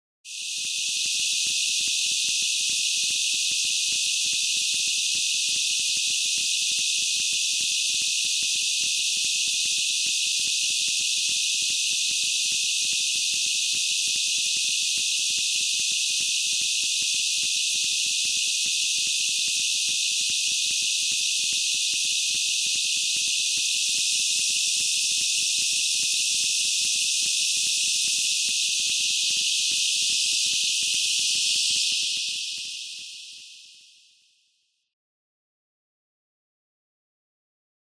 描述：环境，紧张的音景和隆隆声基于正在运行的火车内的环境/声场麦克风录音。
Tag: 地铁 电子 声景 混响 氛围 处理 奇怪 隆隆声 电影 列车 介绍 高铈 戏剧 科幻 噪声 气氛 地铁